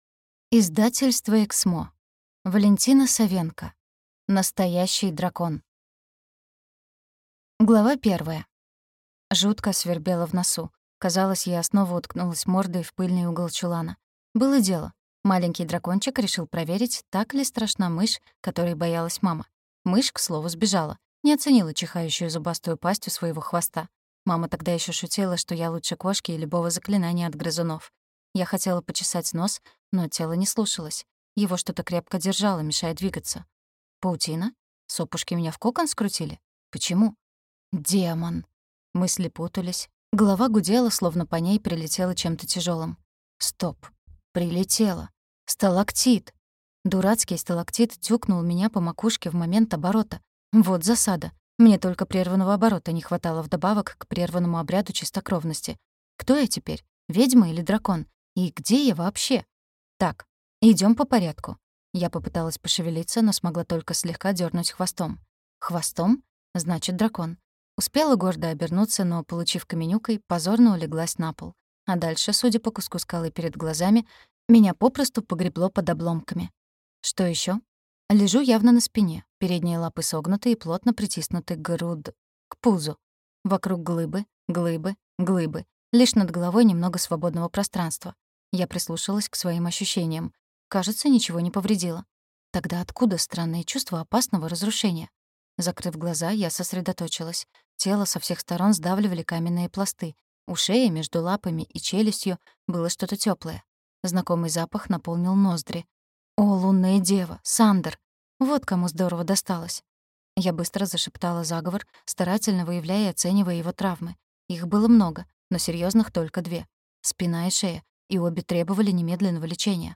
Аудиокнига Настоящий дракон | Библиотека аудиокниг